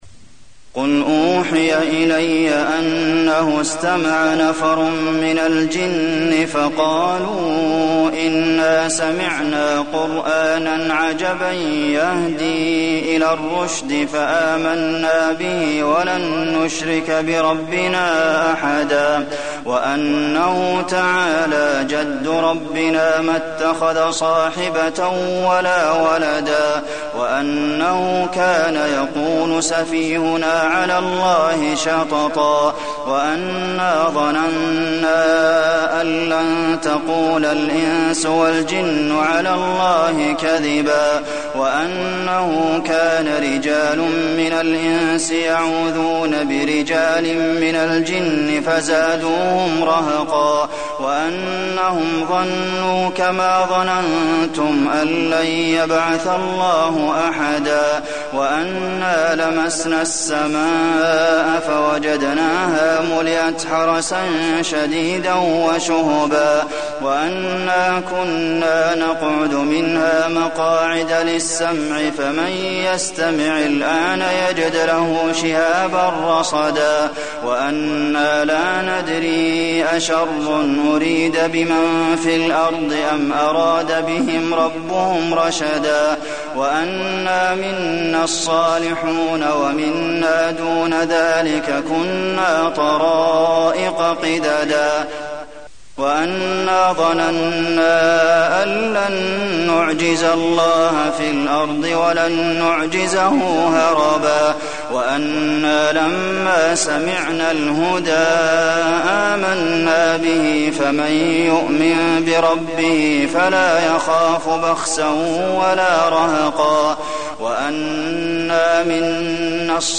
المكان: المسجد النبوي الجن